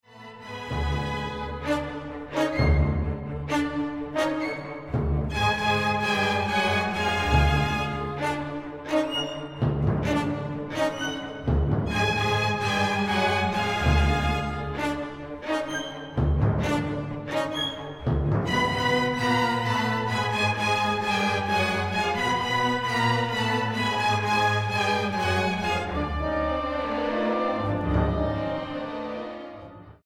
para pequeña orquesta
Allegro molto – Andante – Allegro – Andante